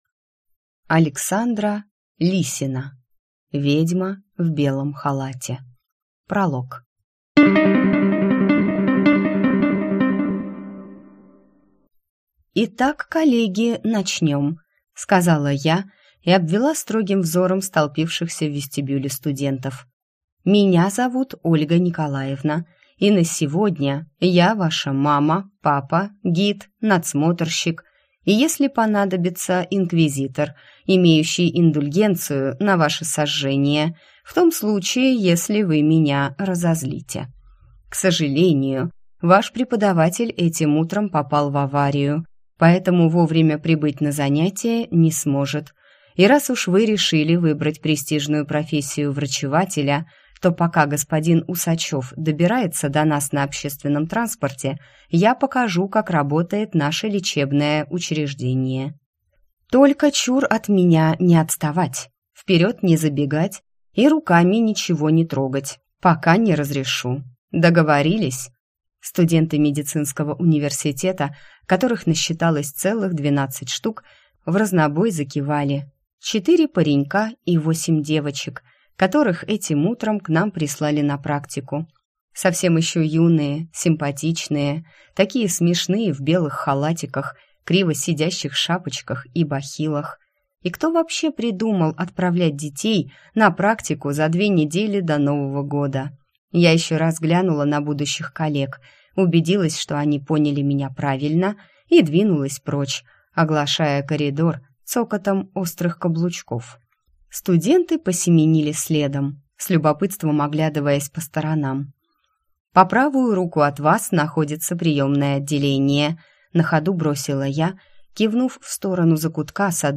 Прослушать фрагмент аудиокниги Ведьма в белом халате Александра Лисина Произведений: 42 Скачать бесплатно книгу Скачать в MP3 Вы скачиваете фрагмент книги, предоставленный издательством